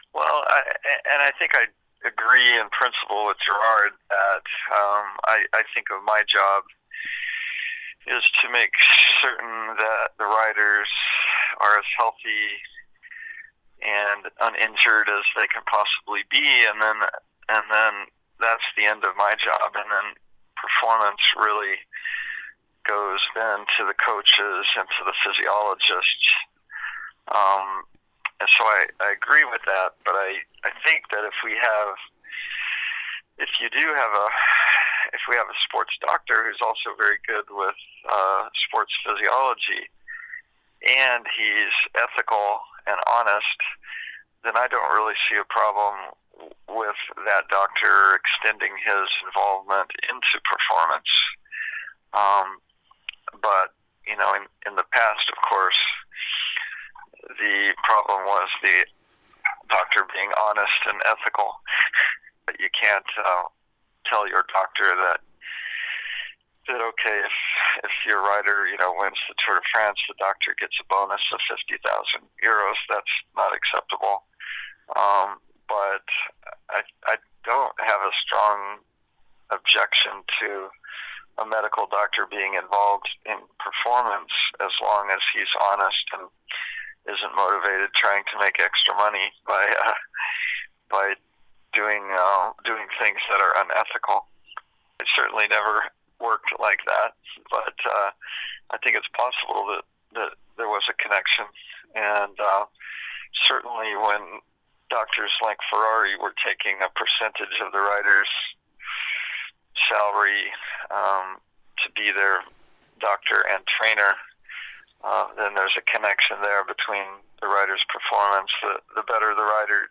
Can a medicine of performance be credible ? Interview